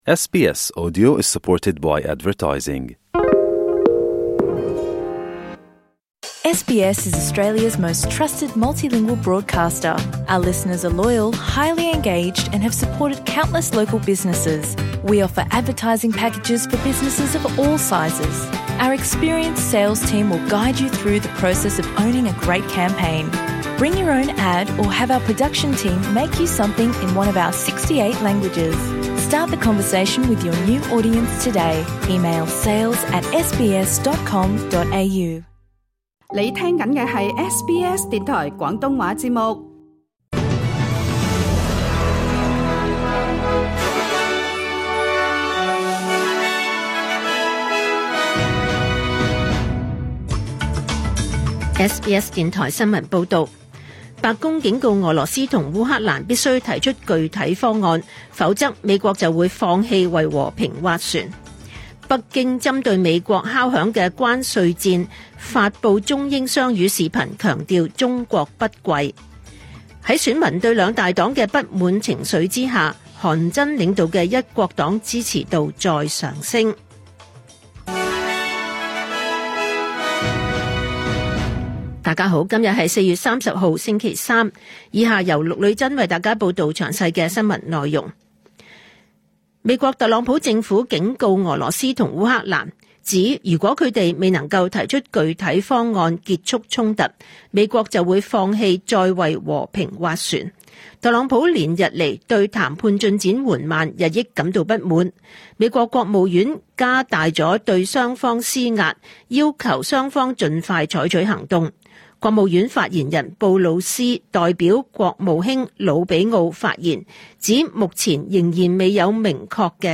2025 年 4 月 30 日 SBS 廣東話節目詳盡早晨新聞報道。